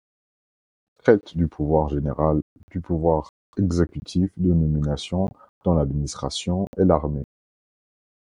Read more Noun Verb Verb trait Noun Verb Verb Read more Frequency B2 Pronounced as (IPA) /tʁɛt/ Etymology From Latin trāditor.